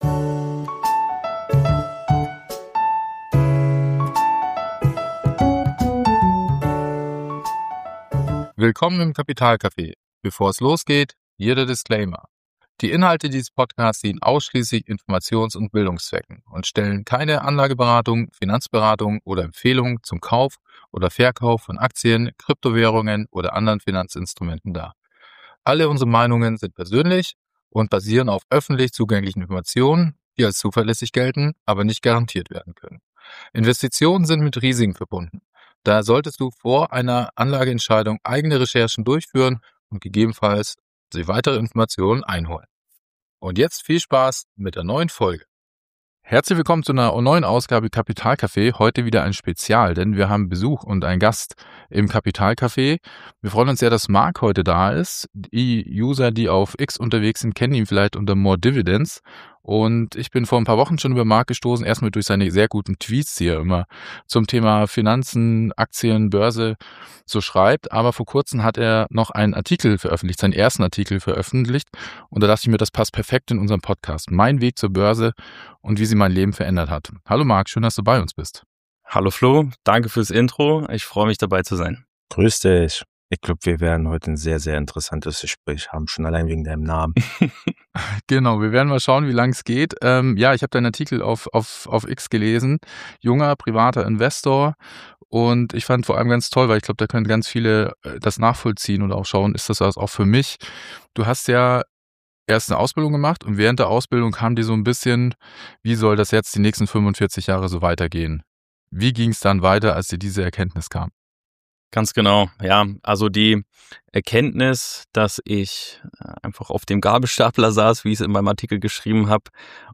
Ein ehrliches, motivierendes Gespräch über Lernen, Rückschläge, Eigenverantwortung und langfristiges Investieren.